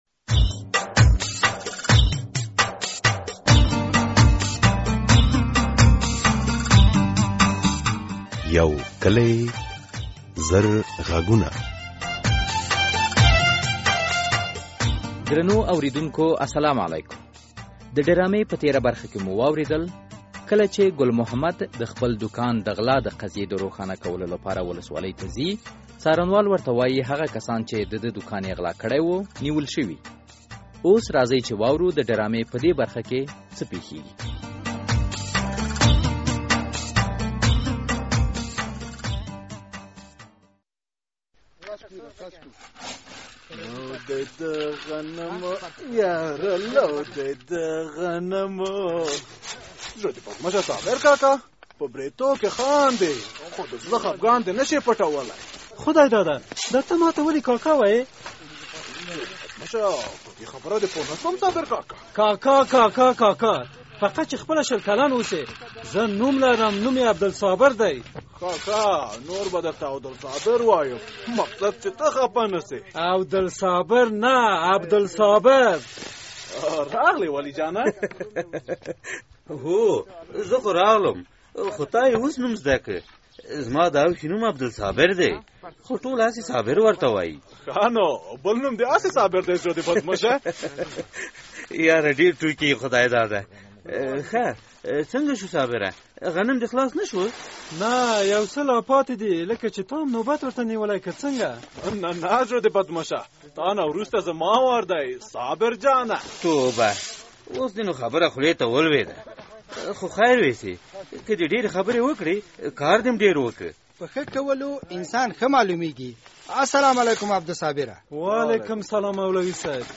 د یو کلي زرغږونو ډرامې ۱۰۵ برخه